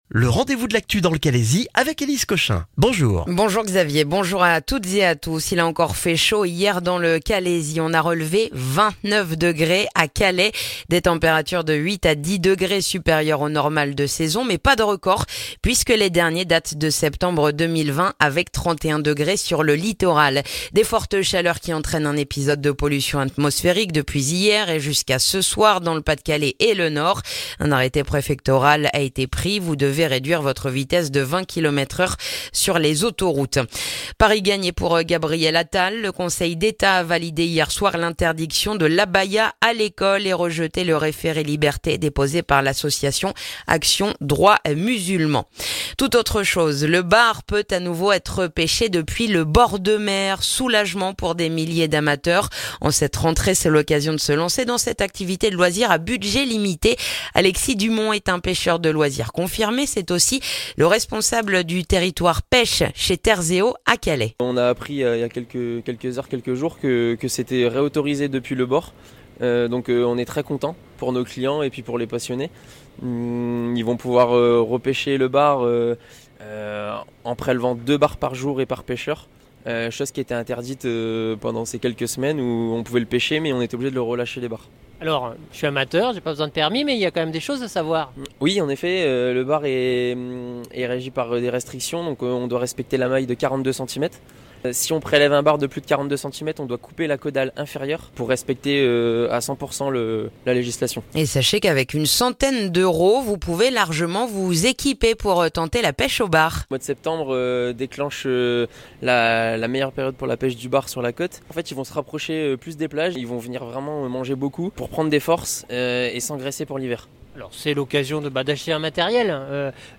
Le journal du vendredi 8 septembre dans le calaisis